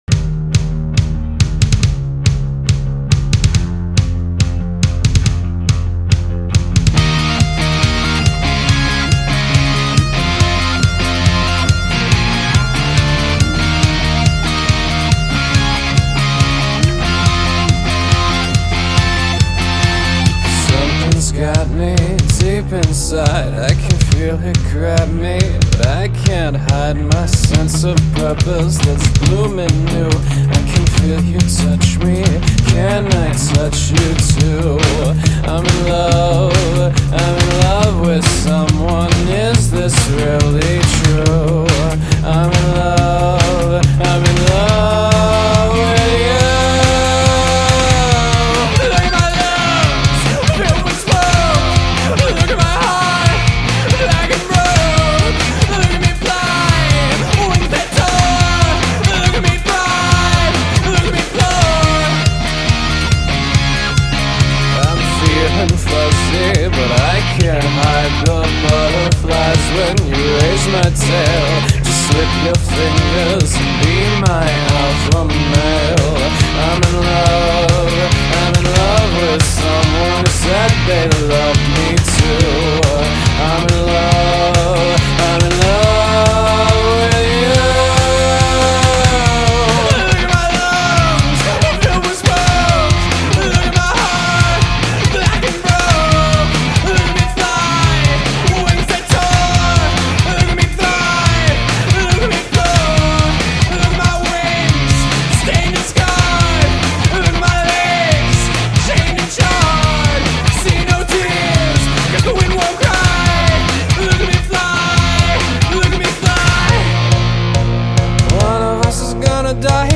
★ credits/info ★ This is a furry banger.